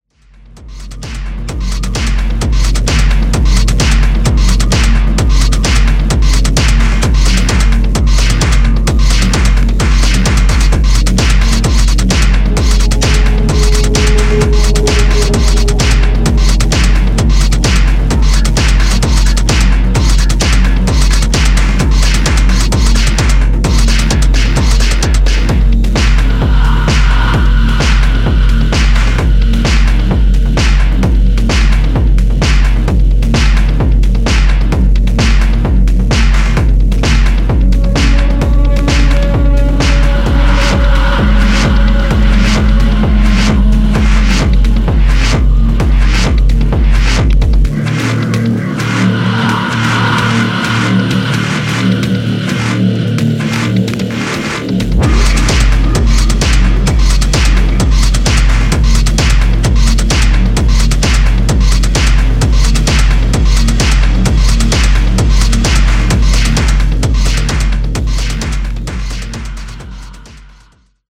Electro Techno Wave